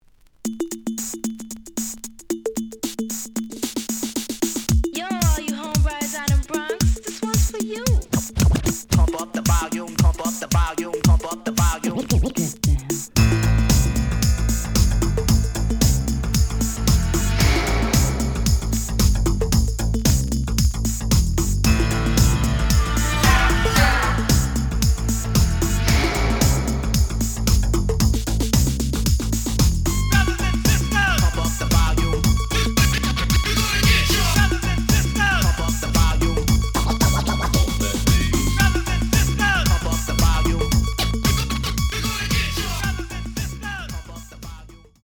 The audio sample is recorded from the actual item.
●Format: 7 inch
●Genre: House / Techno